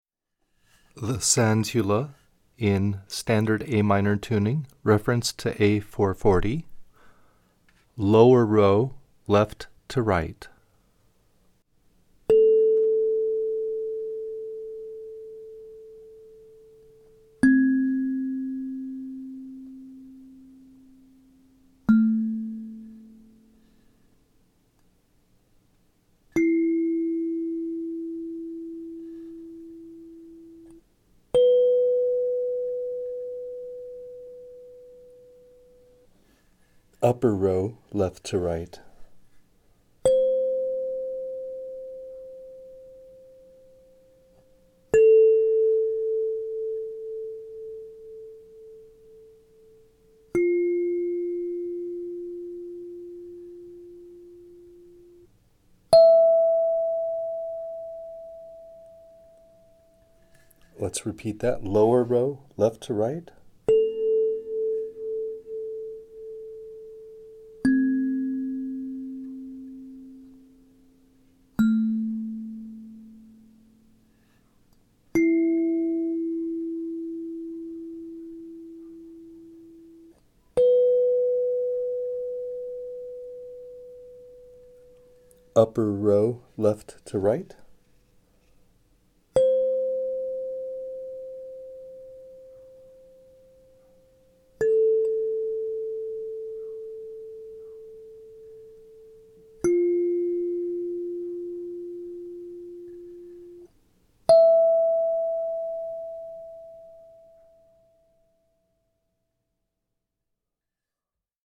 These sound recordings – where you hear me playing each note, properly tuned, slowly, from left to right, for some of our most popular kalimbas – should help you transition into successfully maintaining your instrument’s tuning.
Sansula, Standard A minor
sansula_Aminor.mp3